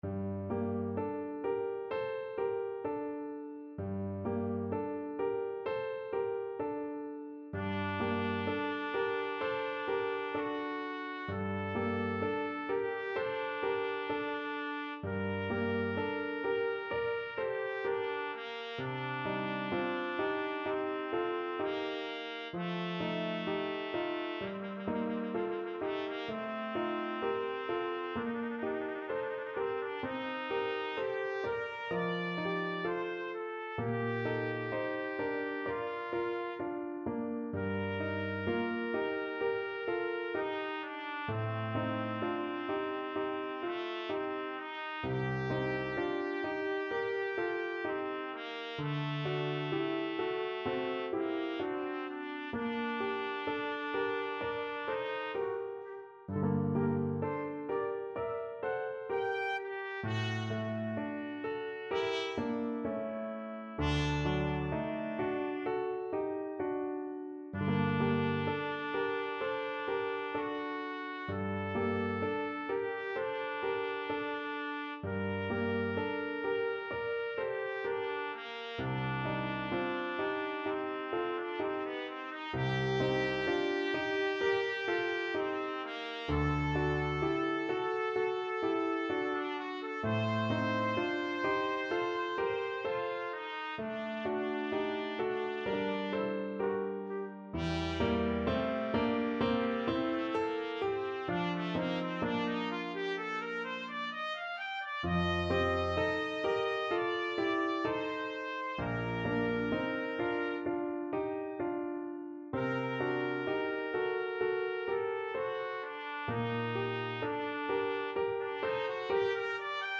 Trumpet
G minor (Sounding Pitch) A minor (Trumpet in Bb) (View more G minor Music for Trumpet )
4/4 (View more 4/4 Music)
Andante sostenuto =32
Classical (View more Classical Trumpet Music)